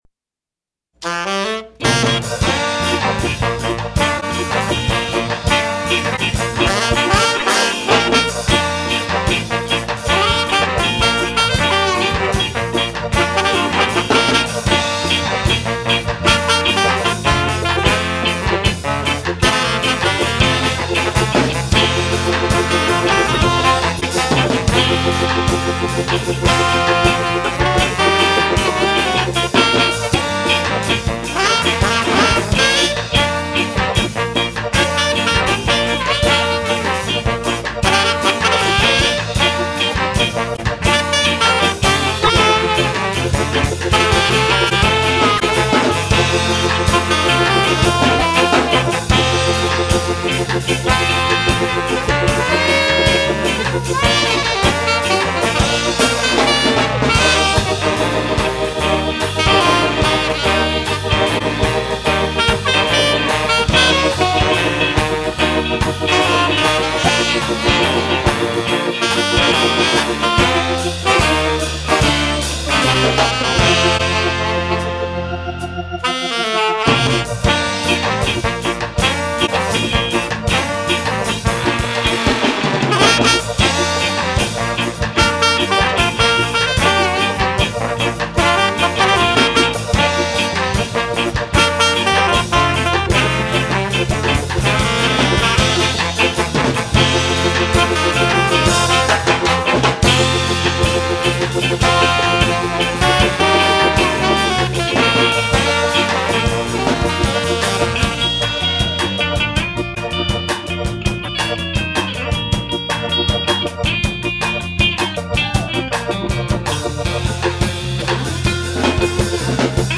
Recorded live at 70Hurtz studio in Argyle, TX 1996